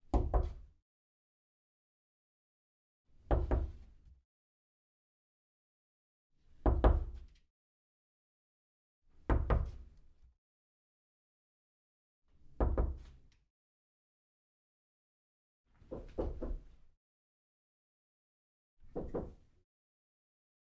随机的" 敲击木质卧室门附近的房间不错的各种
描述：敲木卧室门附近宽敞漂亮各种各样